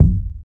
Percu16C.mp3